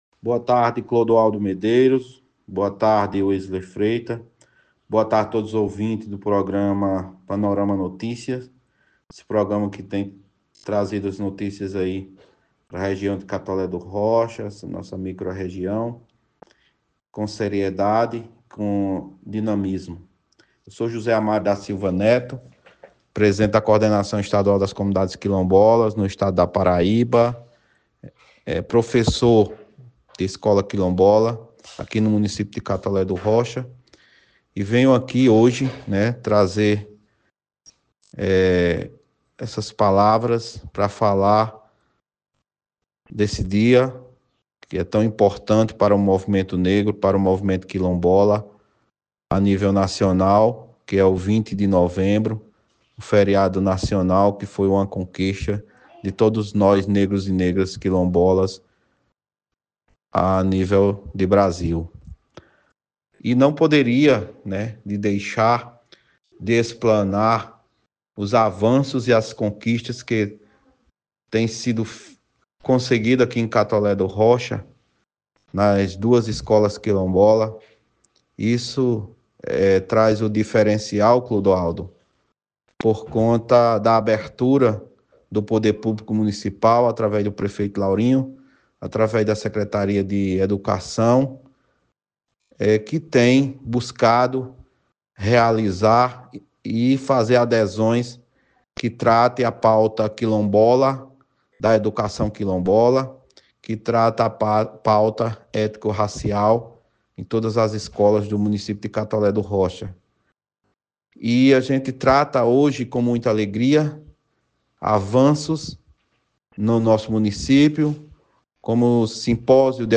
Durante a entrevista